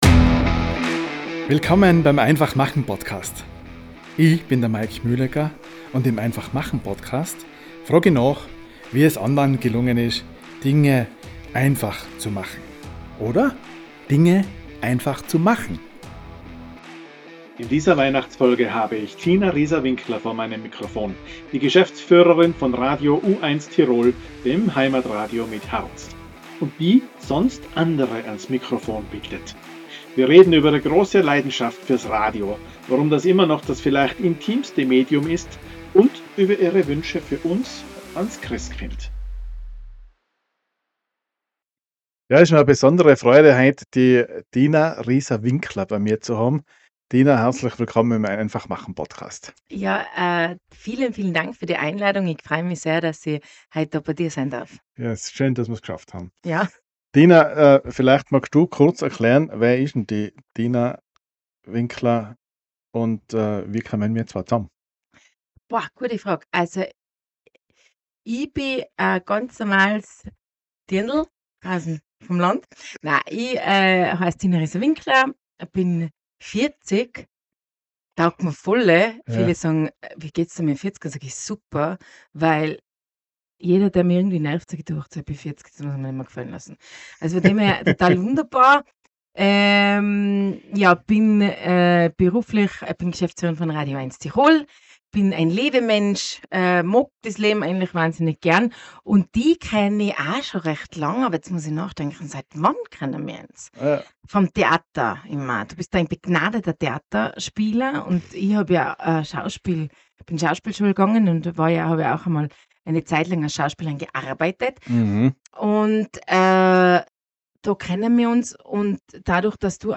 Radio mit Herz: Im Gespräch mit